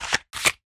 Shuffle.mp3